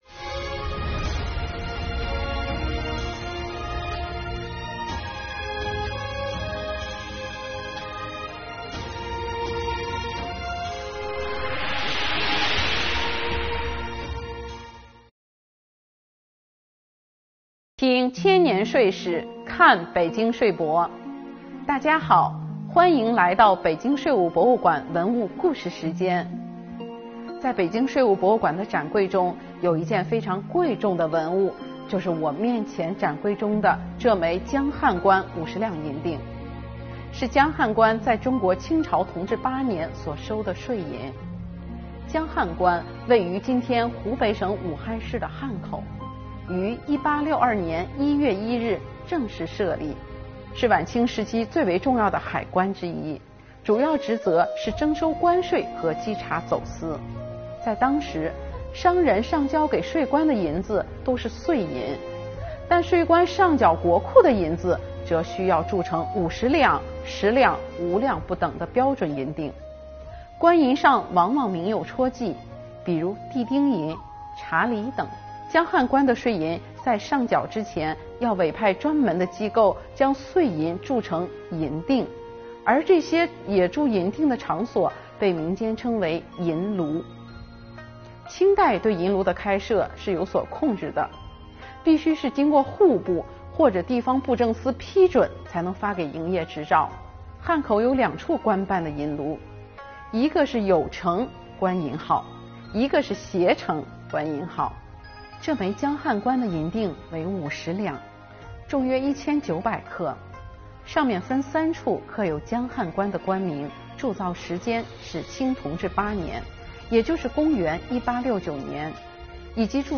北京税务博物馆文物故事讲述人为大家讲述江汉关这枚50两银锭背后的故事↓